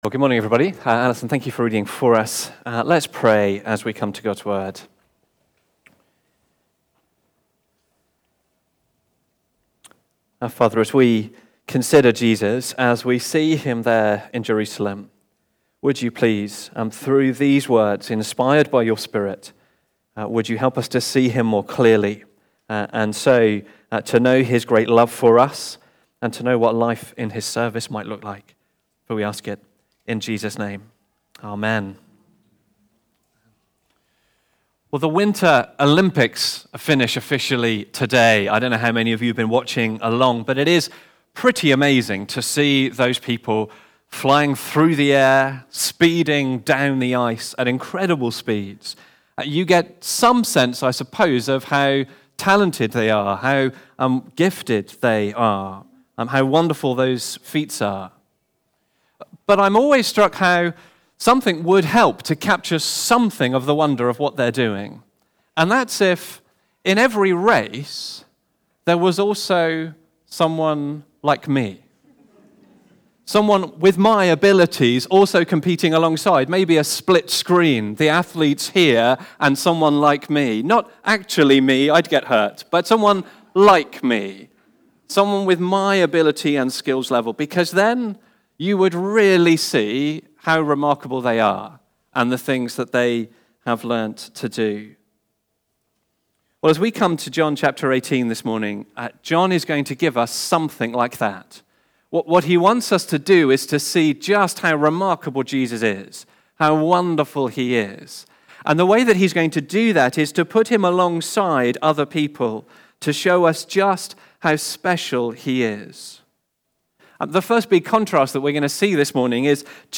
The Denial (John 18:15-27) from the series Life From Death. Recorded at Woodstock Road Baptist Church on 22 February 2026.